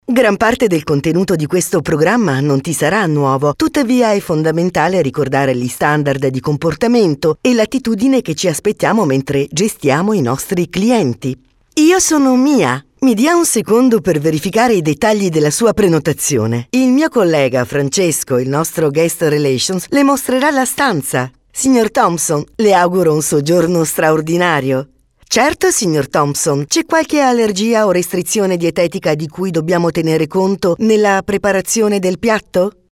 have been working at my professional home studio for 13 years, I record everything and have a flexible voice.
Sprechprobe: eLearning (Muttersprache):
e learning .mp3